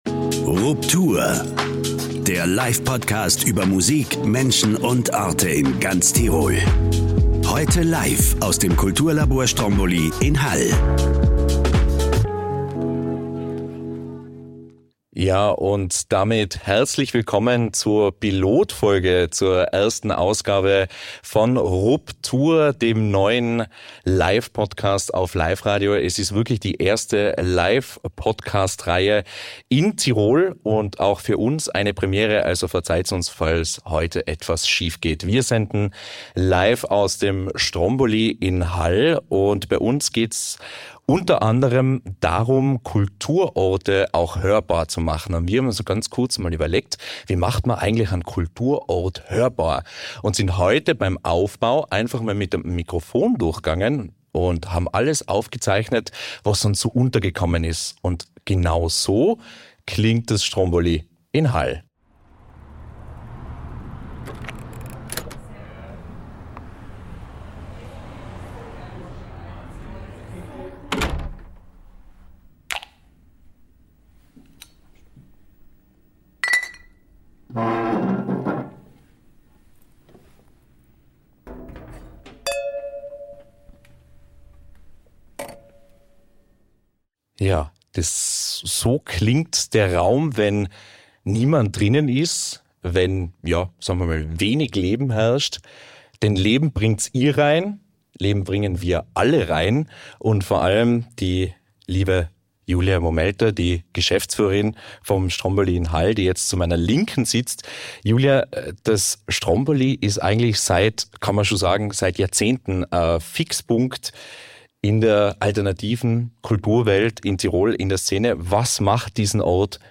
Tirols Livepodcast über Musik, Menschen und Orte zu Gast im Kulturlabor Stromboli Hall.